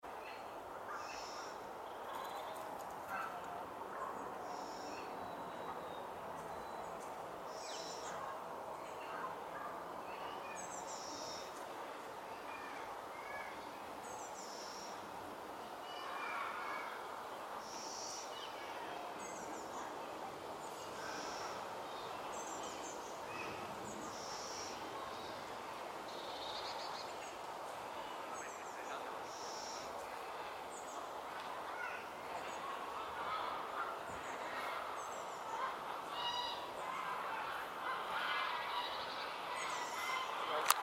Little owlets heard
There are definitely owlets in the Little Owls' nest tree at the Round Pond. They aren't visible at the moment but you can hear them in a
The Great Tit in the recording sounded personally affronted that you were paying attention to the owlets and not him!
LittleOwlet.mp3